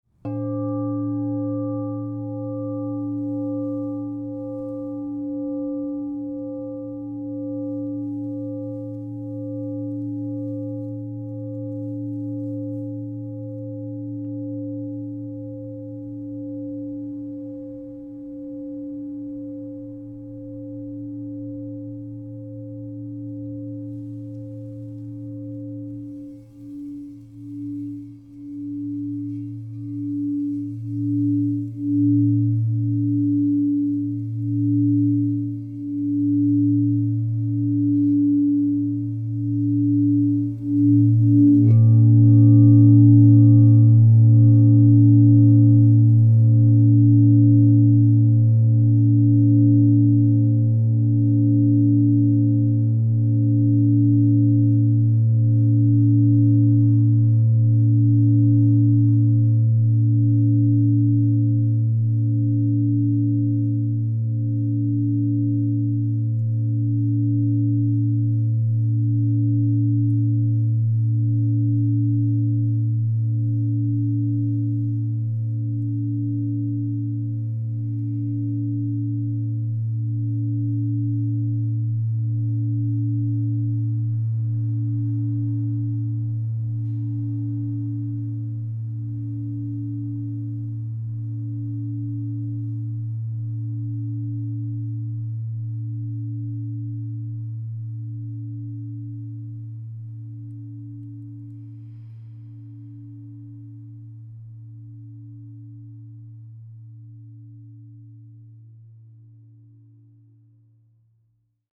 Supergrade Androgynous Indium 14" A +45 Crystal Tones singing bowl
The expansive 14-inch size delivers rich, powerful tones, making it ideal for group sound healing sessions or creating a sanctuary of peace.
Discover the power of 14″ Crystal Tones® alchemy singing bowl made with Supergrade Androgynous Indium in the key of A +45.
528Hz (+)